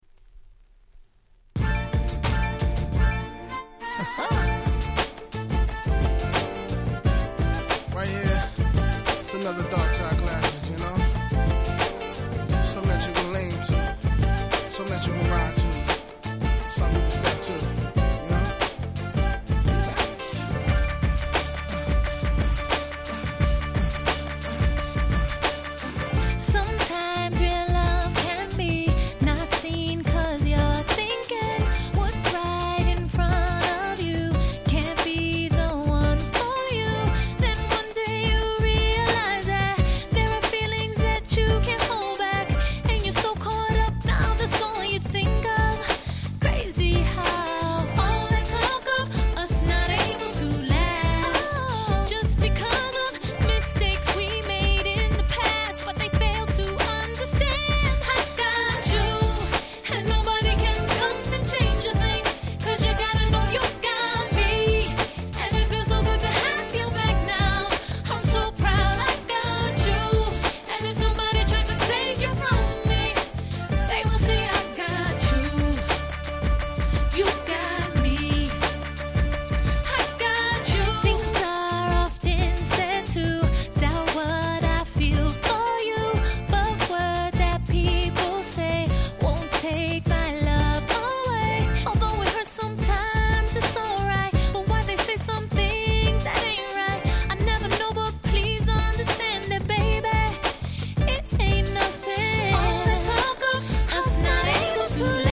05' Super Hit R&B Album.